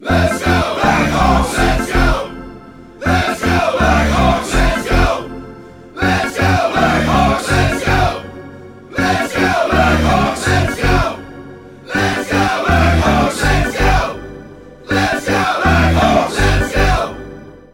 sport-fans-let-s-go-blackhawks-let-s-go.mp3